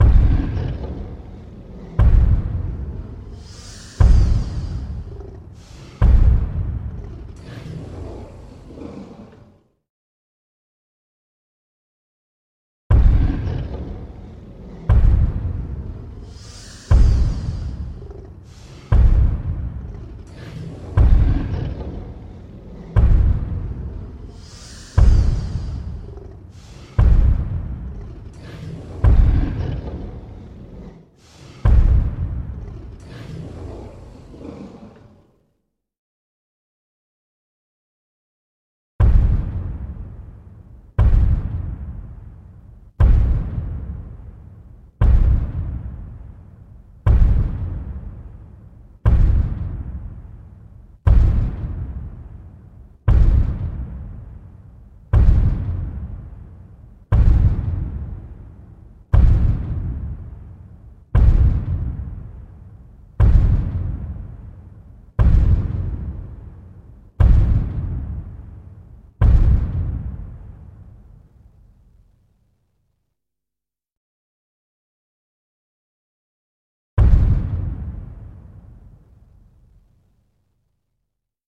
Громкий топот Тираннозавра Рекса